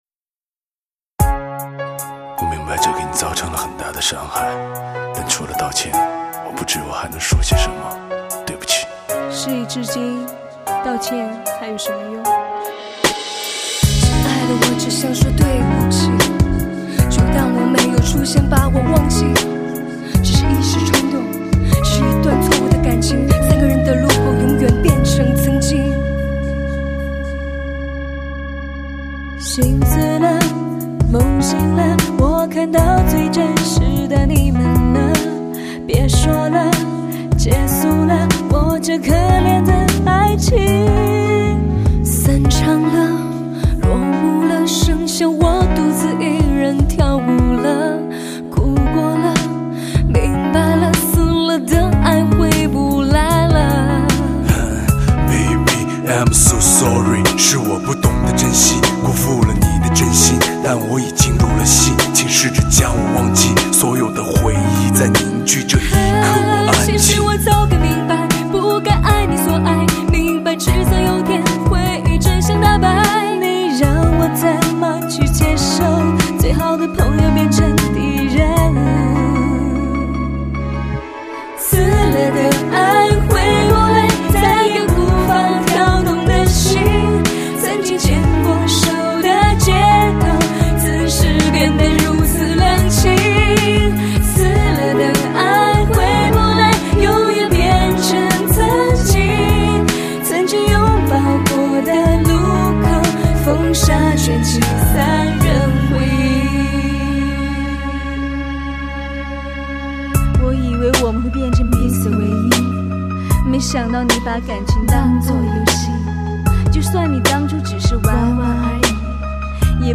唱功卓越 温暖真挚
情歌、抒情、真诚、入耳、细腻 是这张专辑的风格